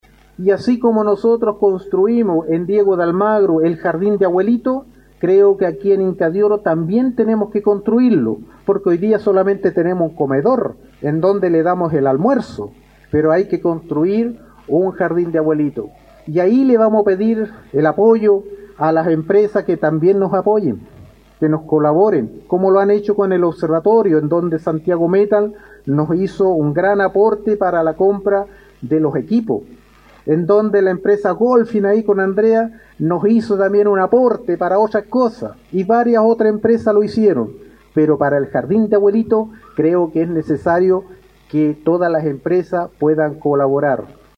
En la localidad de Inca de Oro se realizó un significativo acto de inauguración de una piscina municipal.
El alcalde Zavala expresó su interés en poder replicar en Inca de Oro la buena experiencia que ha sido el Jardín de Abuelitos, sobre pensando en que las personas que ya están en edad muy avanzada, tengan un buen lugar donde estar y compartir, por lo que hizo un llamado a las empresas de la zona a que puedan colaborar en ese proyecto, como ya lo han hecho en otros: